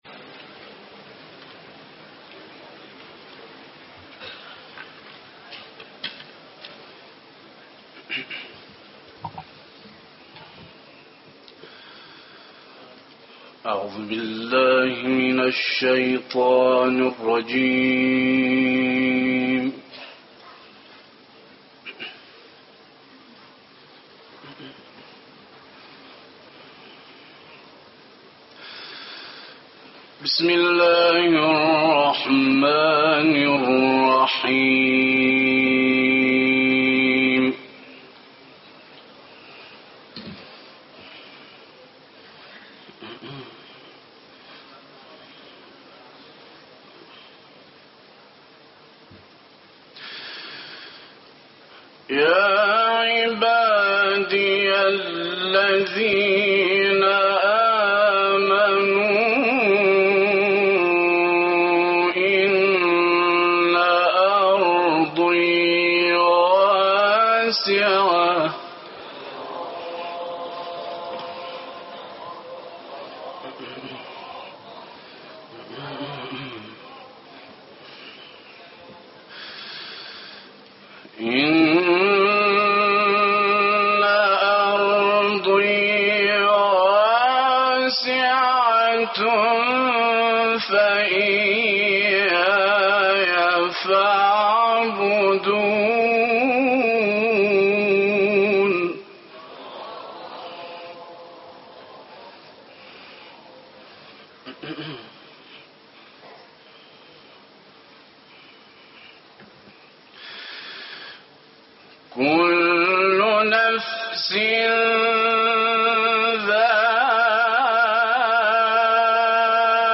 دانلود قرائت سوره عنکبوت آیات 56 تا 64 و کوثر